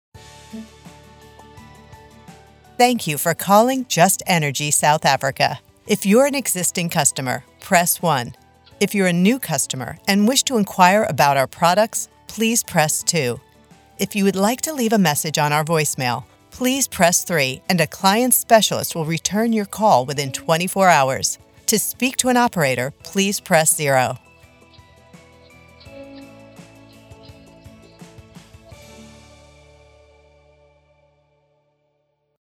Professional On Hold Phone Message | Business Phone Greetings & Recordings
0709Telephony-Greeting_mixdown.mp3